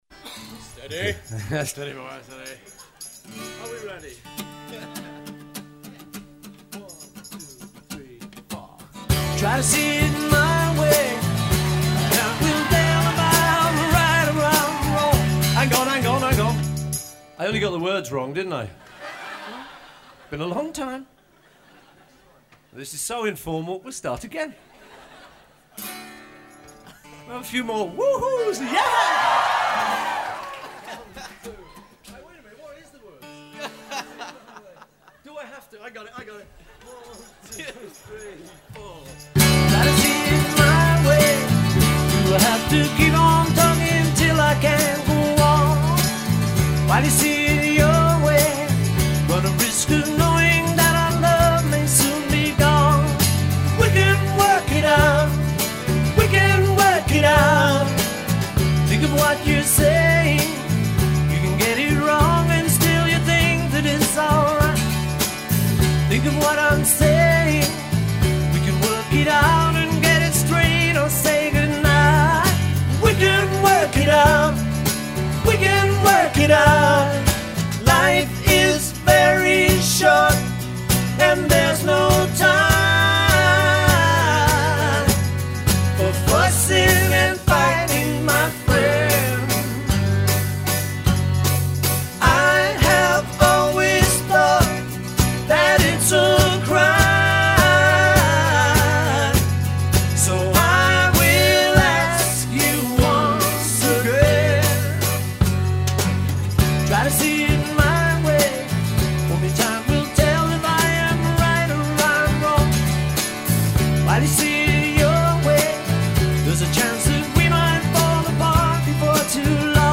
perform acoustically